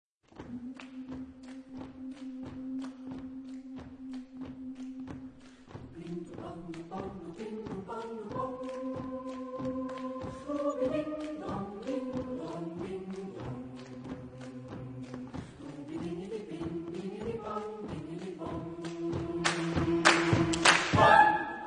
Texte en : anglais ; onomatopées
Genre-Style-Forme : Canon
Type de choeur : SAH  (3 voix mixtes )